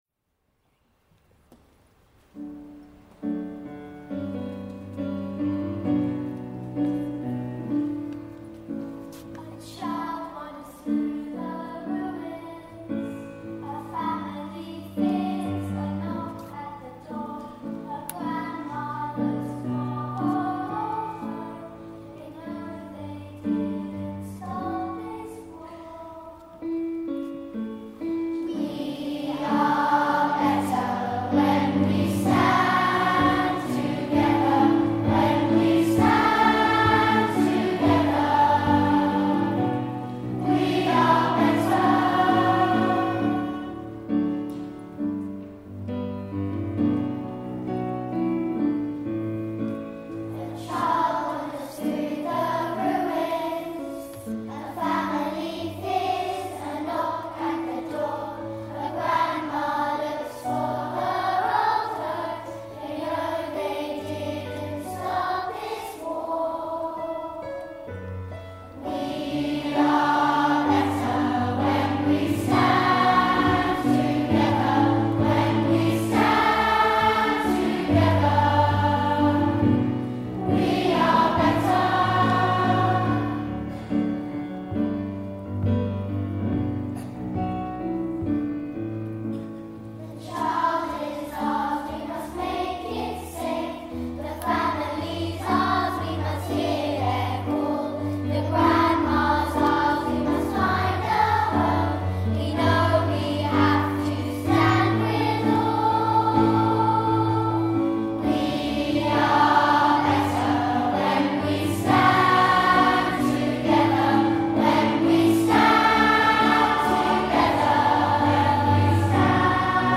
(solo / small group)
(full choir)
Chorus (2-part round; part 2 enters on *)